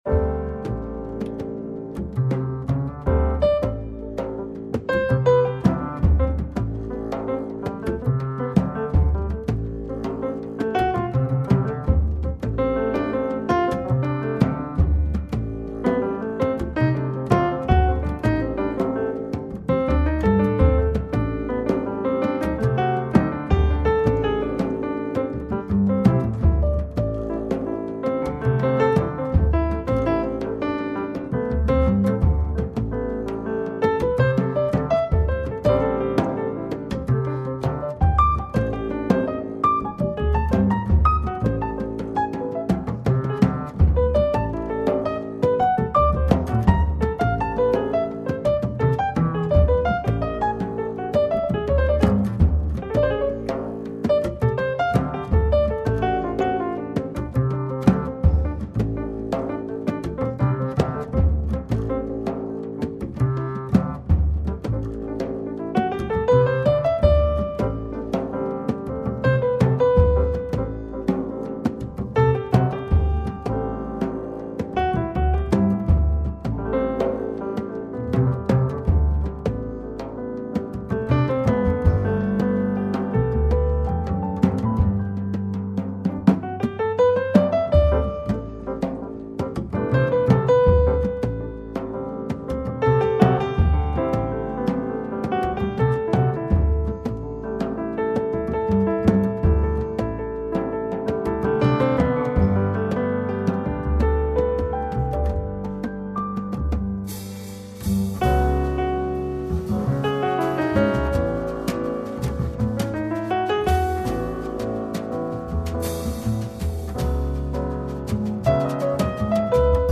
Registrato in studio il 25 Giugno 2007.
Piano
Doublebass
Drums